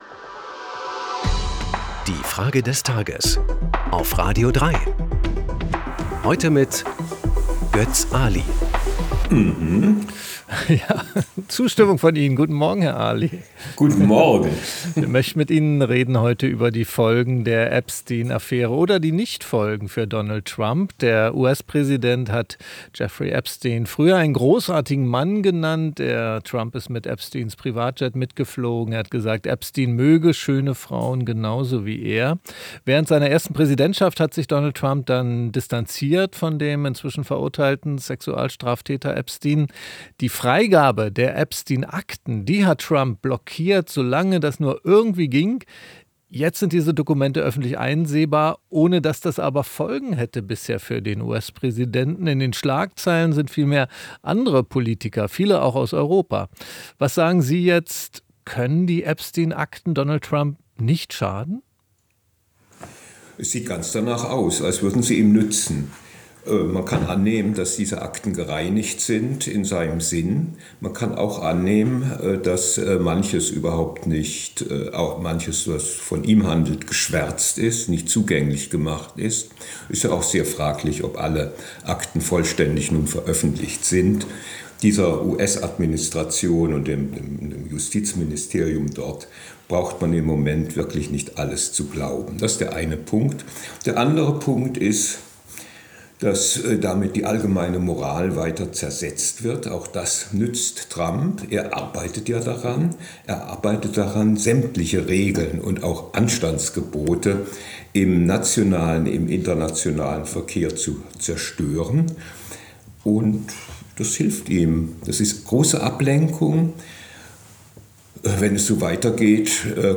Es antwortet der Politikwissenschaftler und
Historiker Götz Aly.